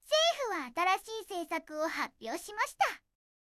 referenceの音声と書き起こしを入力して、Targetのテキストを生成してみました。結構いい感じですが、起伏がオーバーになることが多いのが少し気になりました。
色々パラメータ変えて出力を見てみました。全部同じじゃないですか！